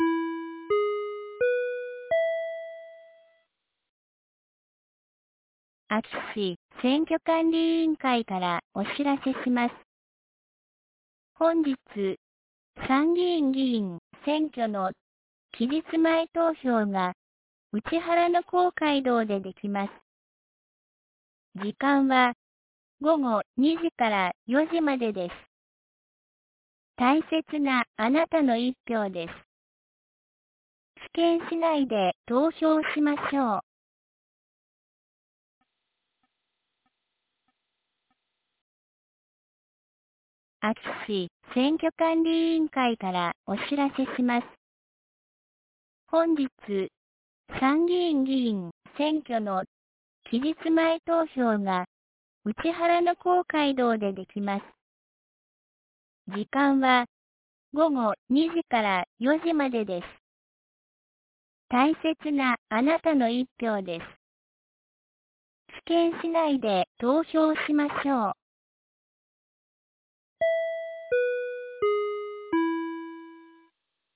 2022年07月07日 12時06分に、安芸市より井ノ口へ放送がありました。